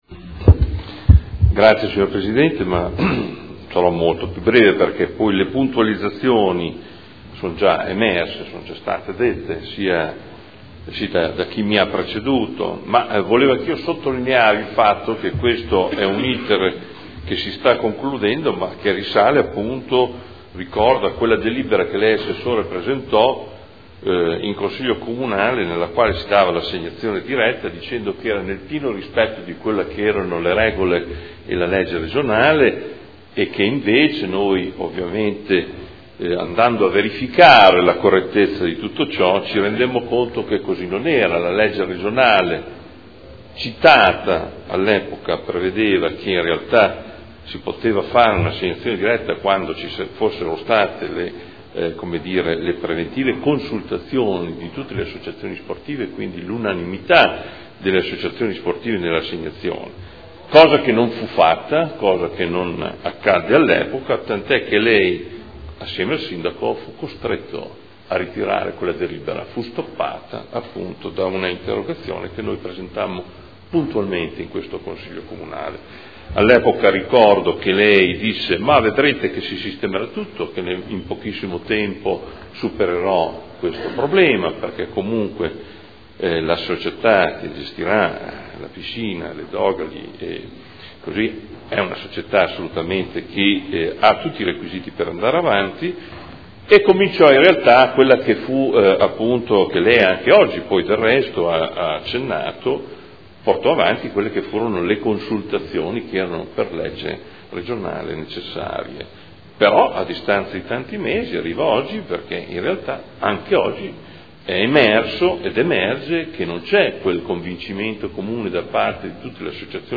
Adolfo Morandi — Sito Audio Consiglio Comunale
Seduta del 16 gennaio. Proposta di deliberazione: Concessione gestione piscina Dogali: indirizzi. Dibattito